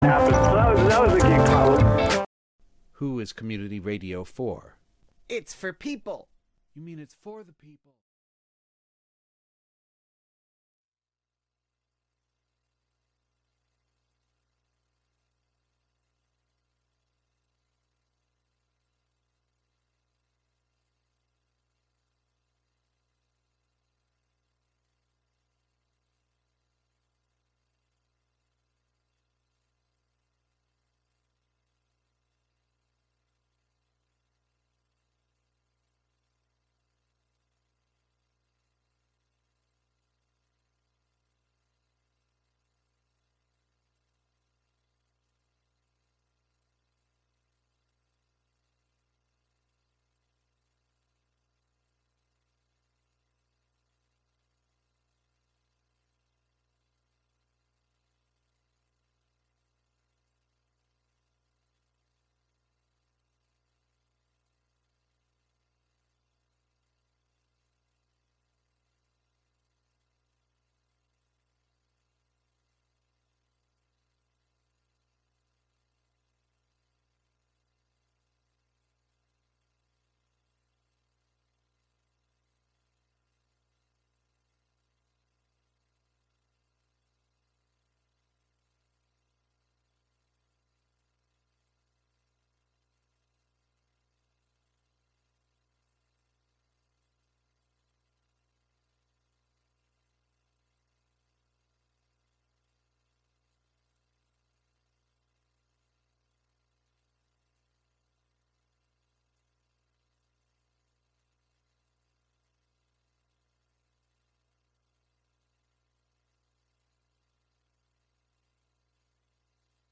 On each show, invited guests are asked to discuss a number of items that they would take with them to their Catskill Cabin get-away.